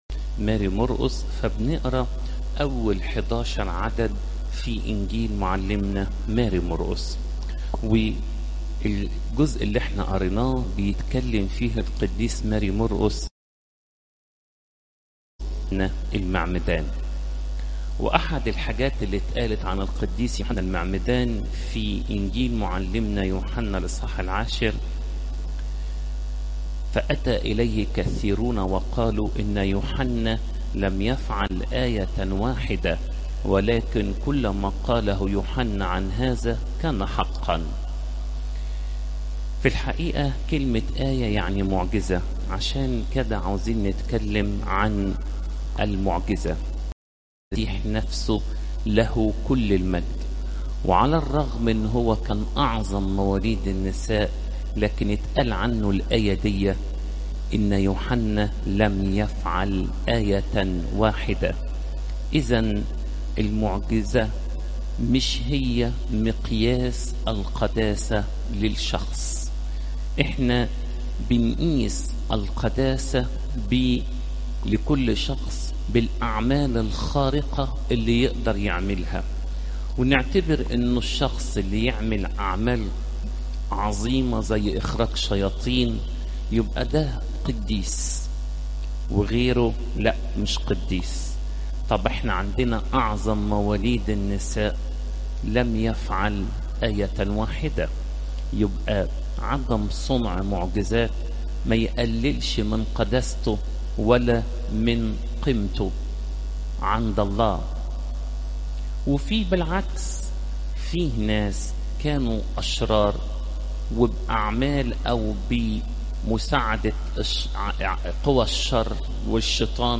تفاصيل العظة
عظات قداسات الكنيسة (مر 1 : 1 - 11)